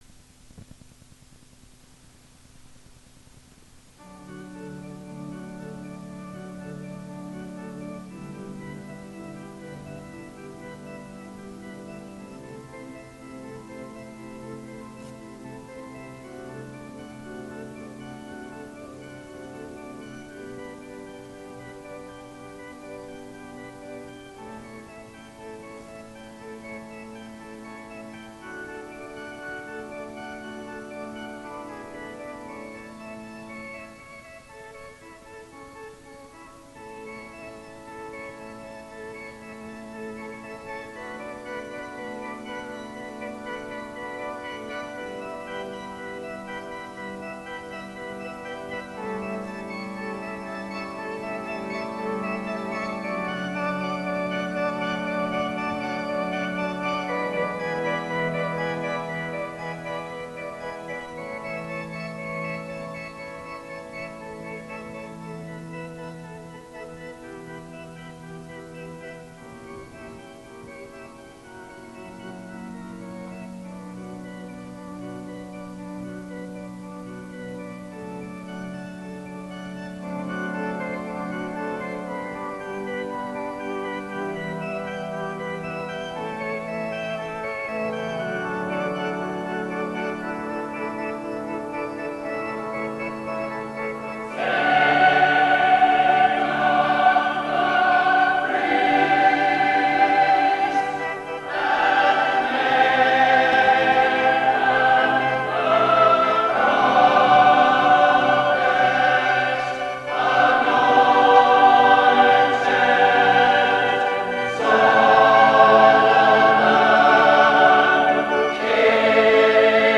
Side A, digitized from cassette tape:
Zadok the Priest, by Handel, coronation anthem for George II, 1727 (senior mixed choir).